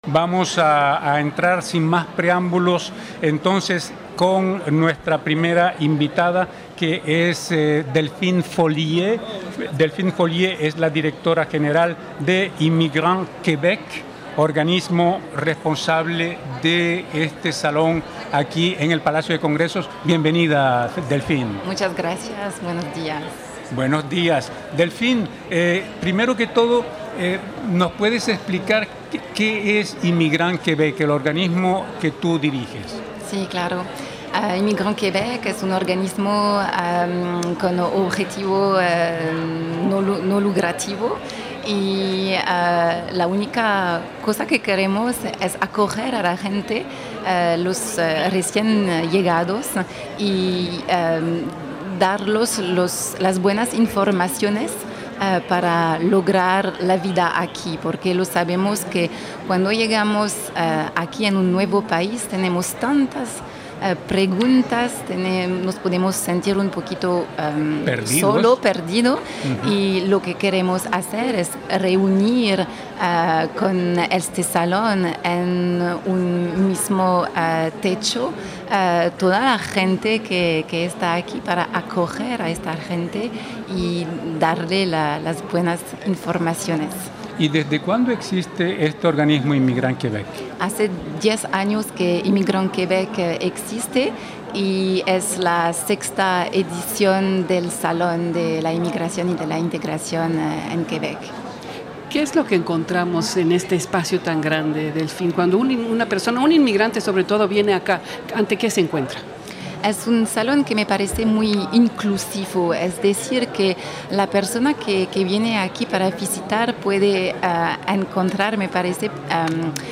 Hoy estamos saliendo de nuestra zona de confort y estamos transmitiendo desde el Palacio de Congresos de Montreal, porque aquí se está llevando a cabo hoy miércoles 31 de mayo y mañana jueves primero de junio, la sexta edición del Salón de la Inmigración y de la Integración en Quebec.